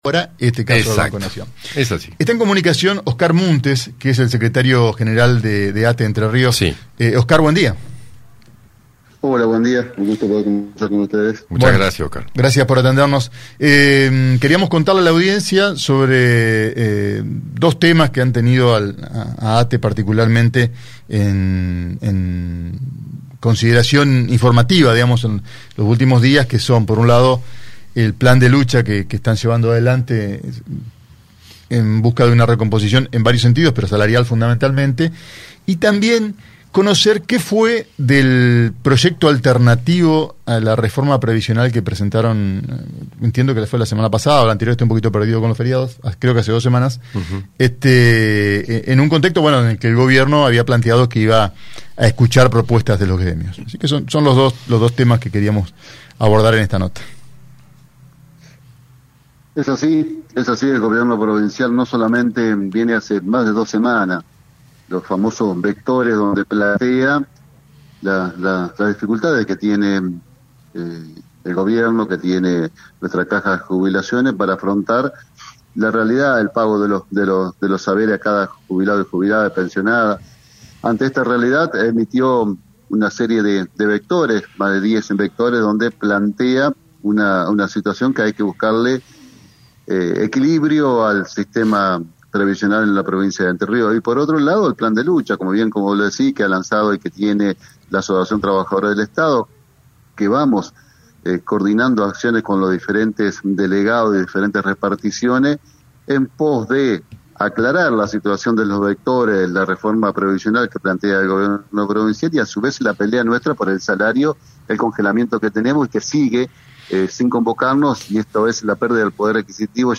en diálogo con el programa Buen Día